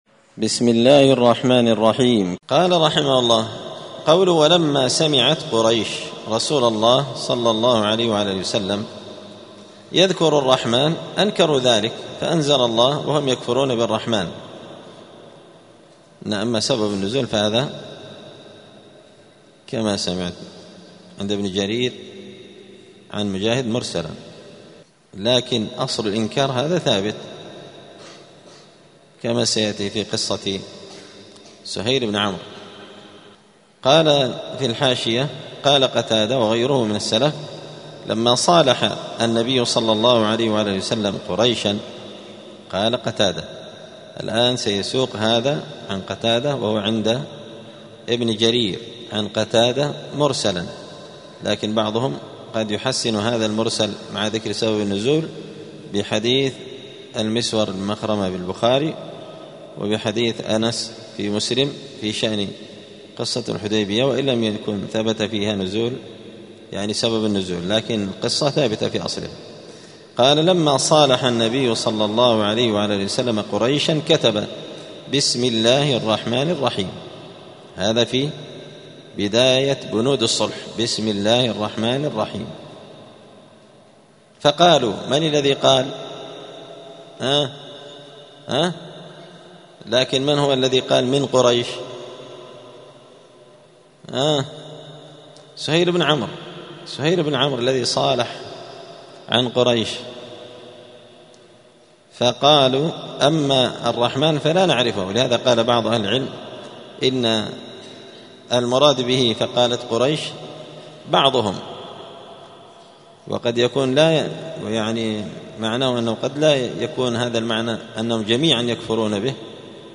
دار الحديث السلفية بمسجد الفرقان قشن المهرة اليمن
*الدرس السابع عشر بعد المائة (117) تابع لباب من جحد شيئا من الأسماء والصفات*